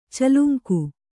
♪ caluŋku